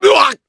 Chase-Vox_Damage_jp_02.wav